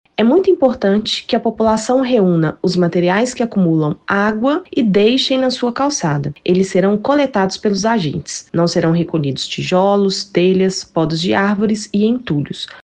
A secretária de Saúde, Ana Clara Meytre, convoca os moradores a participarem deste trabalho preventivo.